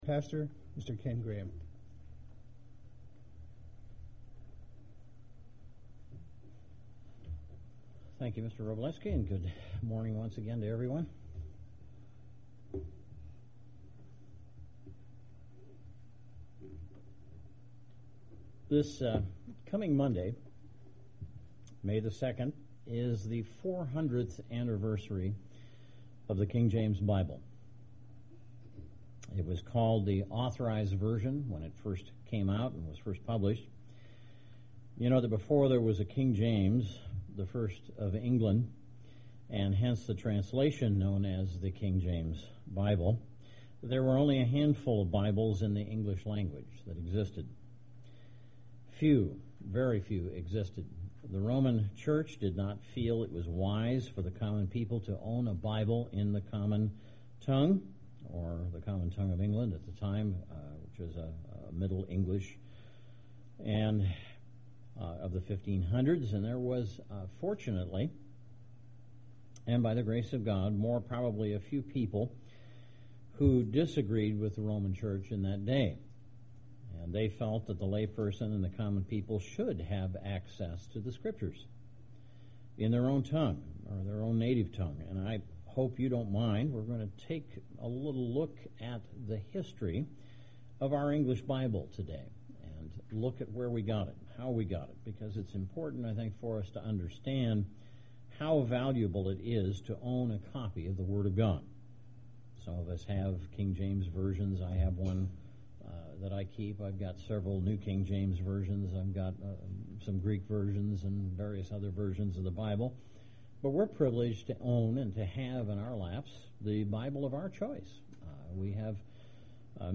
Print History of the development and popularity of the King James Version of the Bible UCG Sermon Studying the bible?